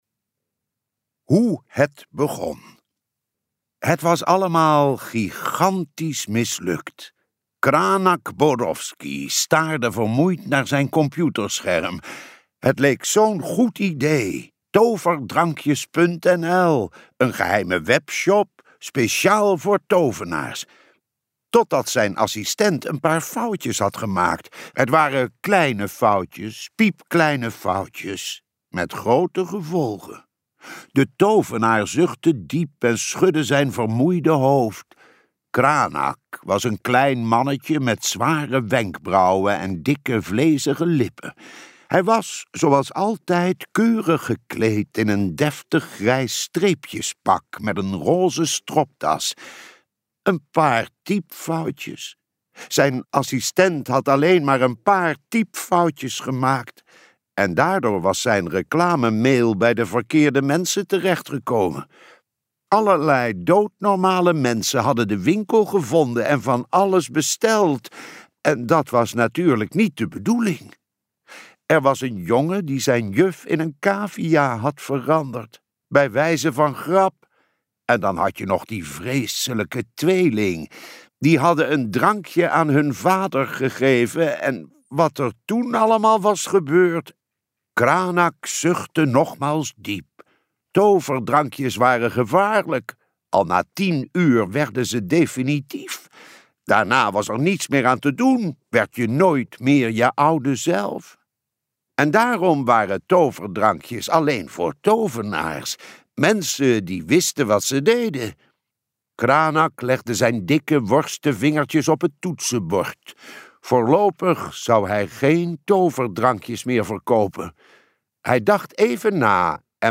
Luisterboek